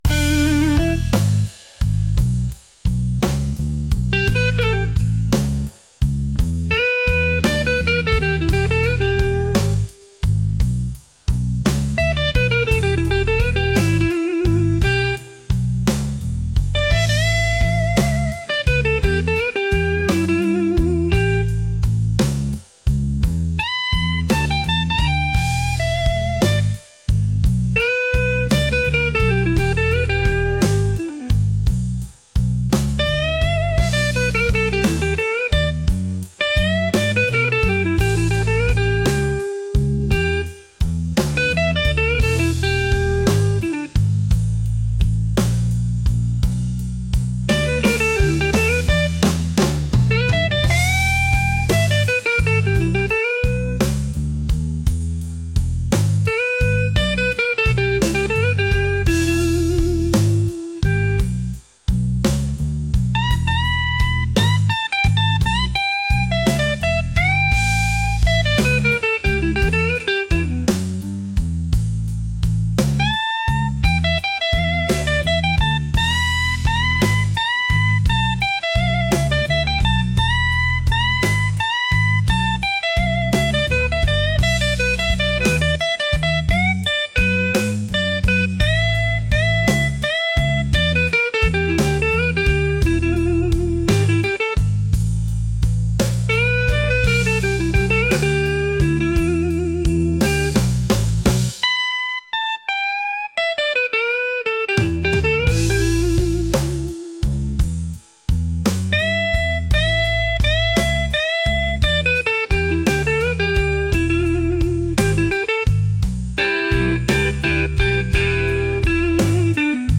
blues | soulful